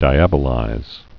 (dī-ăbə-līz)